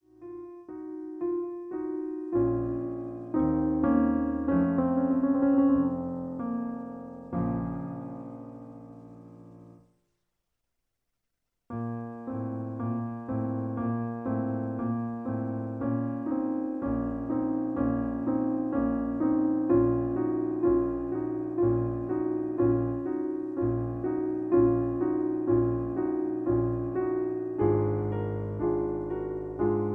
In B flat. Piano Accompaniment